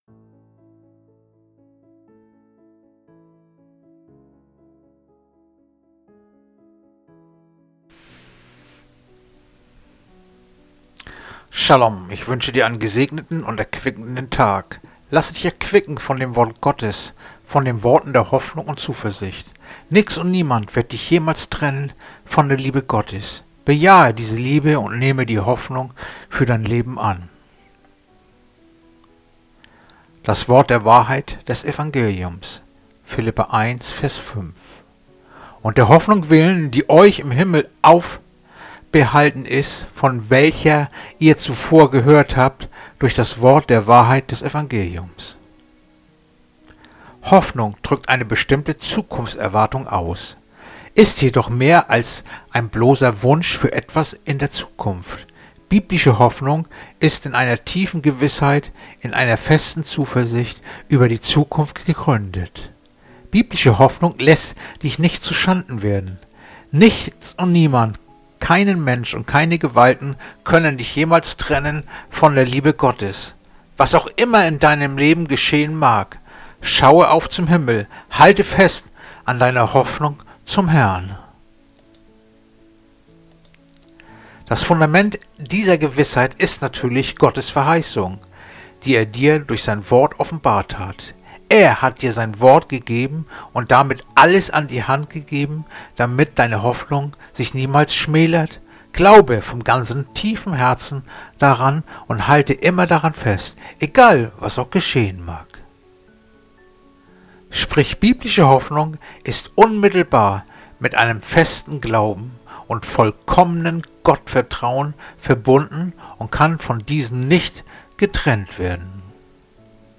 Andacht-vom-11-Februar-Philipper-1-5.mp3